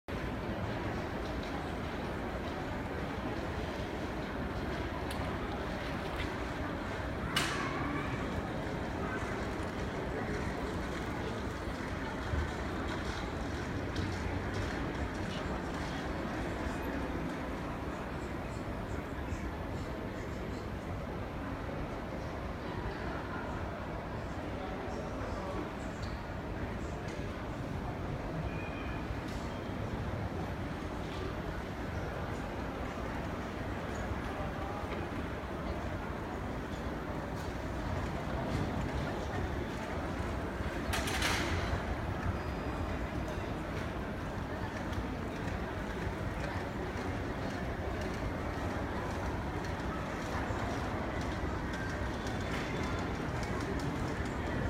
دانلود آهنگ فرودگاه 3 از افکت صوتی طبیعت و محیط
دانلود صدای فرودگاه 3 از ساعد نیوز با لینک مستقیم و کیفیت بالا
جلوه های صوتی